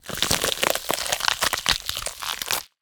Flesh Rip 3 Sound
horror